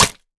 Minecraft Version Minecraft Version latest Latest Release | Latest Snapshot latest / assets / minecraft / sounds / mob / goat / horn_break3.ogg Compare With Compare With Latest Release | Latest Snapshot
horn_break3.ogg